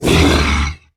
rakghoul_growl.mp3